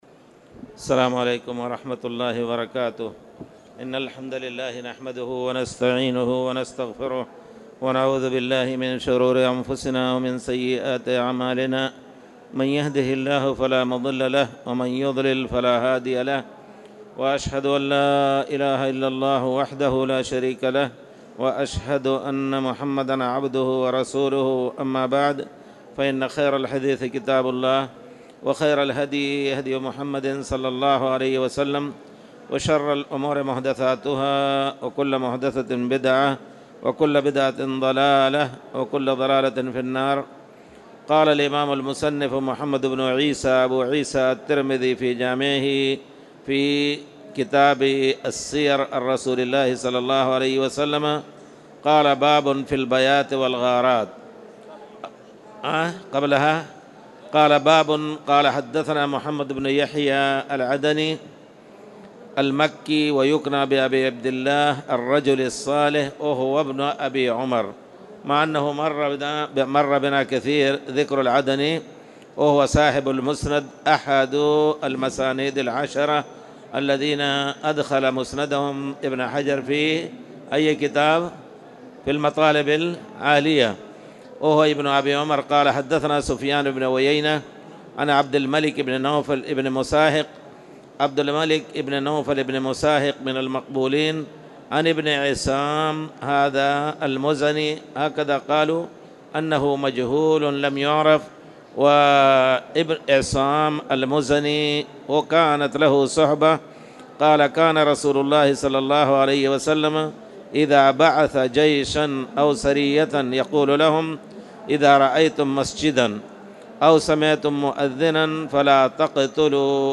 تاريخ النشر ٤ رجب ١٤٣٨ هـ المكان: المسجد الحرام الشيخ